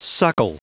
Prononciation du mot suckle en anglais (fichier audio)
suckle.wav